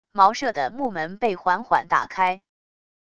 茅舍的木门被缓缓打开wav音频